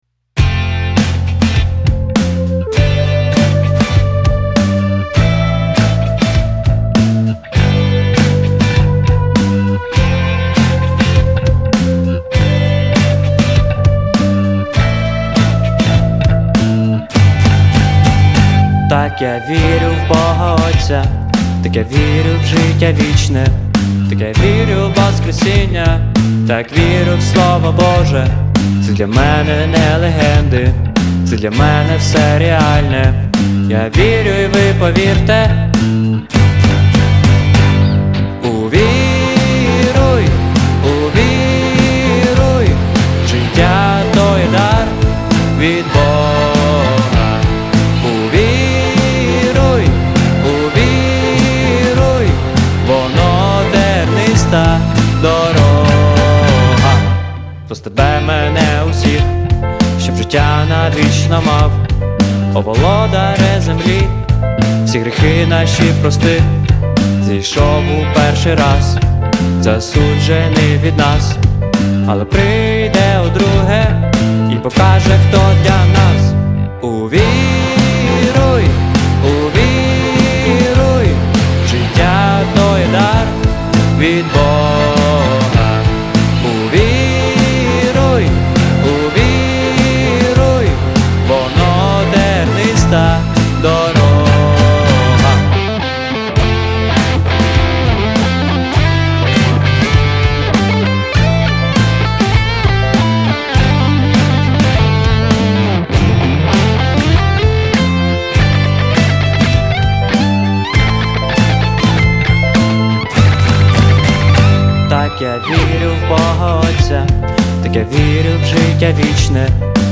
64 просмотра 65 прослушиваний 2 скачивания BPM: 100